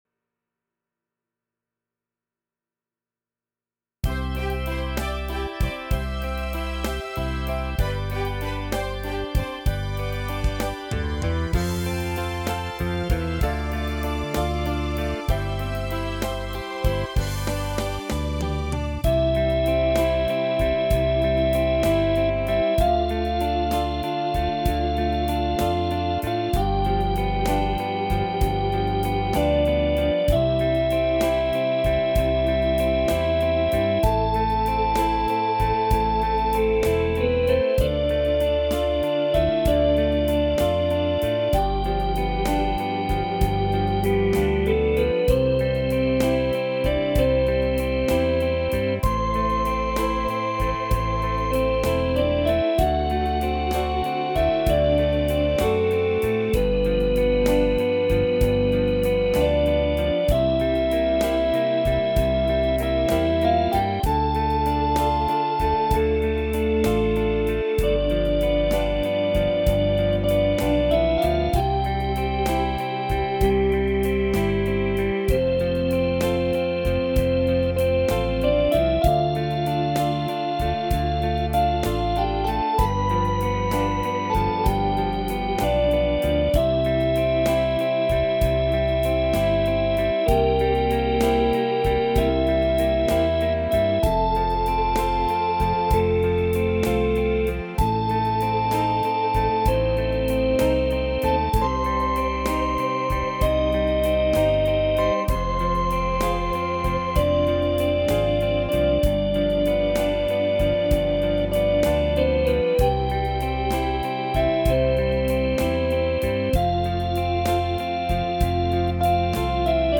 As músicas foram executadas com os seguintes teclados:
MÚSICAS EXECUTADAS COM O TECLADO YAMAHA PSR-SX700